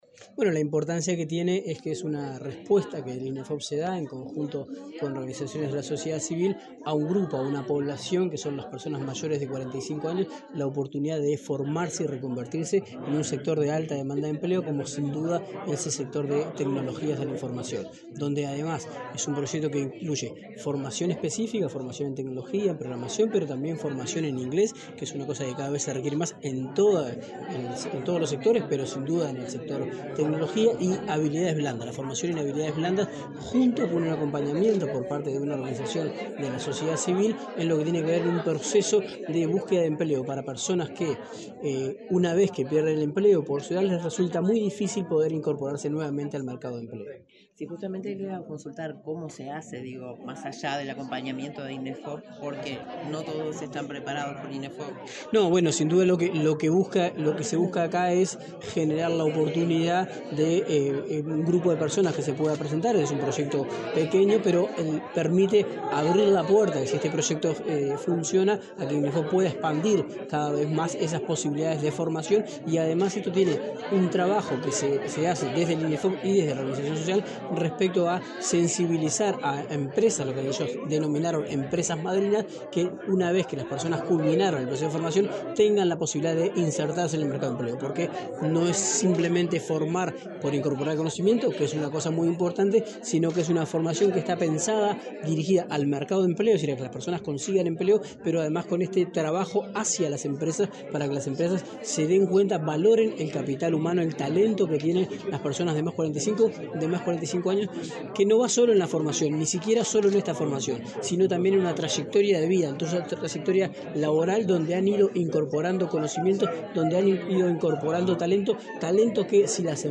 Declaraciones del subsecretario de Trabajo, Daniel Pérez
El subsecretario de Trabajo, Daniel Pérez, dialogó con la prensa, luego de participar en la presentación del programa de capacitación destinado a la